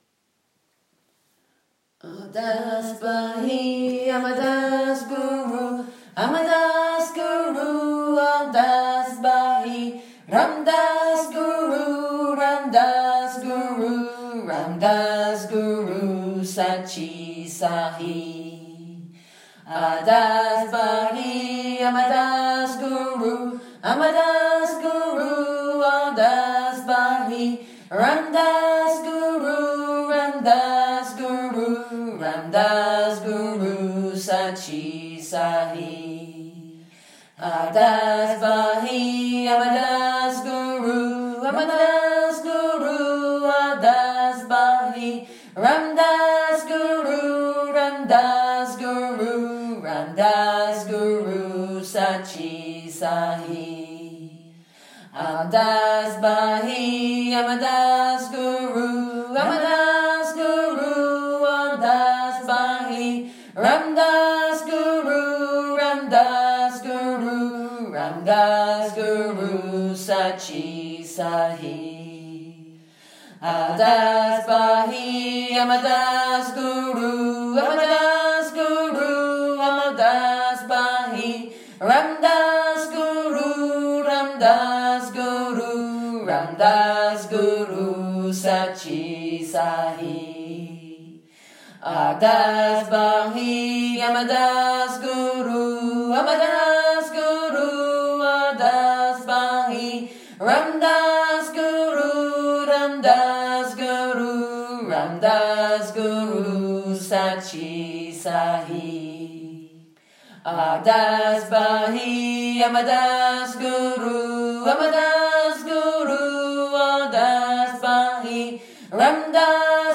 This version might be easier for children to learn…